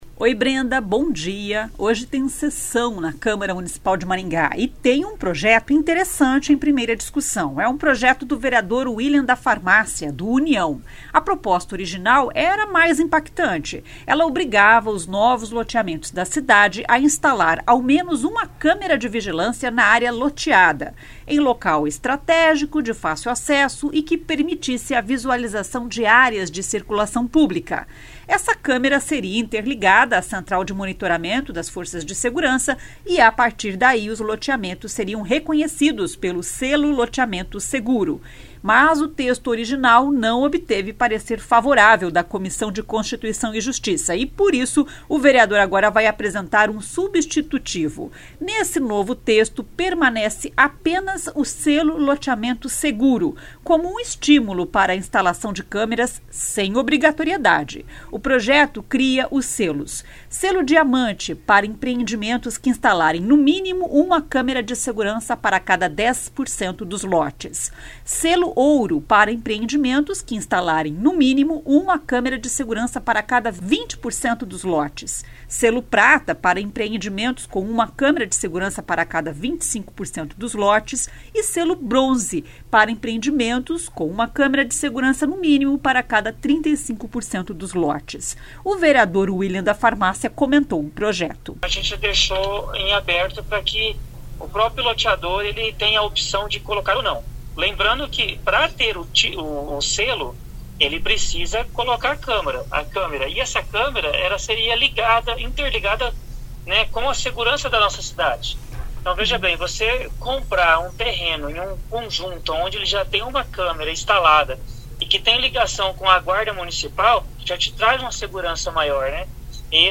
O vereador Uilian da Farmácia comentou o projeto.